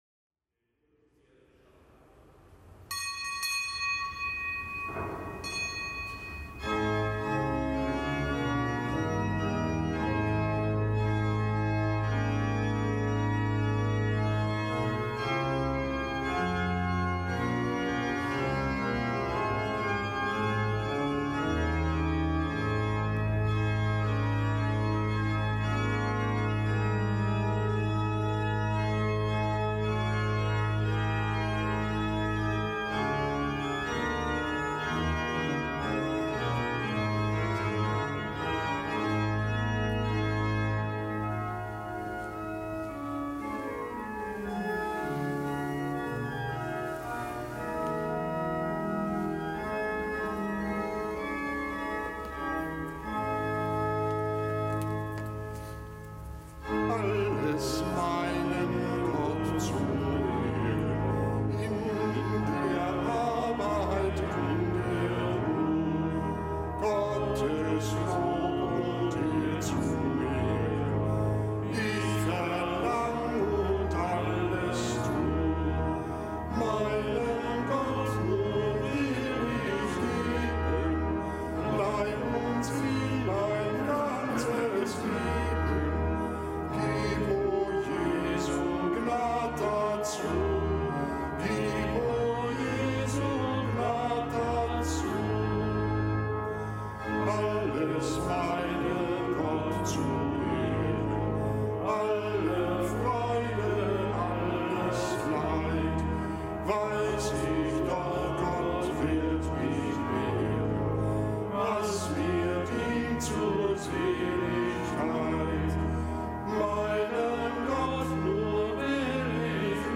Kapitelsmesse aus dem Kölner Dom am Montag der zweiten Woche im Jahreskreis, nichtgebotener Gedenktag des Heiligen Fabian, Papst, Märtyrer und des Heiligen Sebastian, Märtyrer.